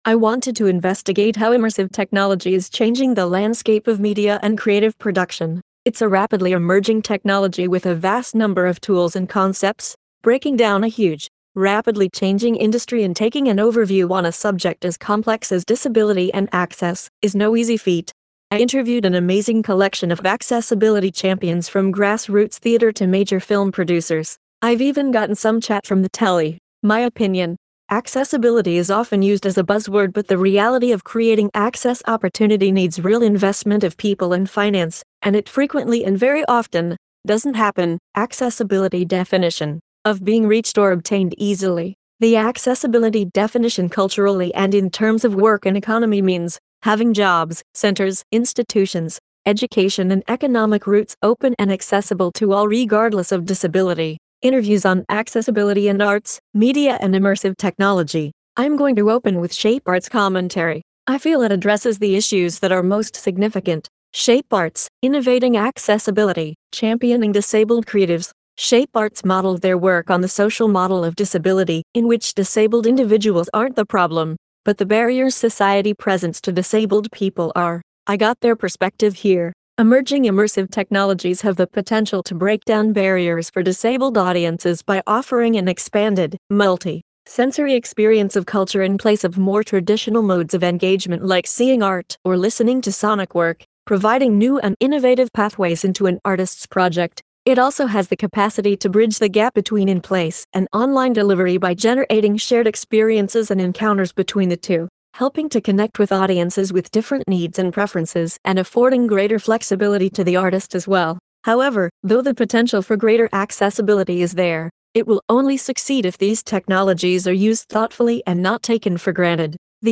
Interviews on Accessibility in Arts, Media & Immersive Technology